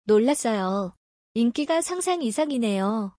ラッソヨ. インギガ サンサン イサンイネヨ